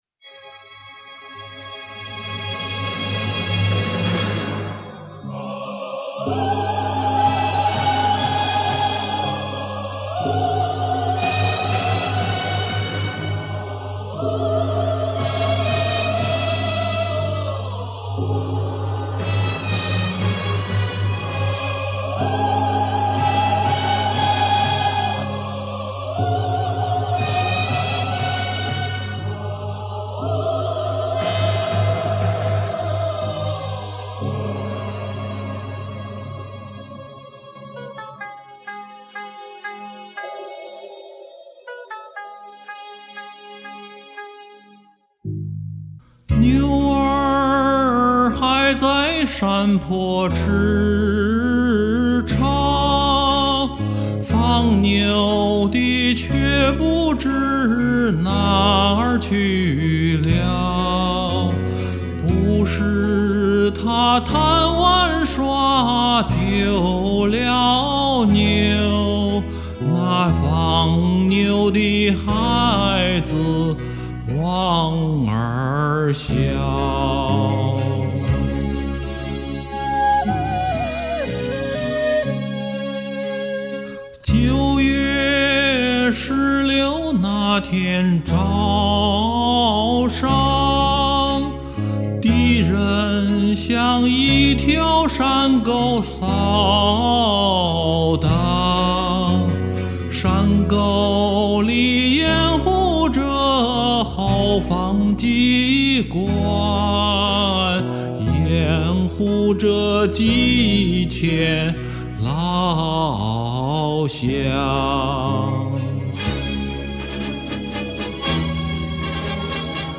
非常熟悉的优美动听的旋律，但歌词太多也有点绕口，也不太熟悉。为节省时间，只好一段一段地录唱。有些字也没有咬好。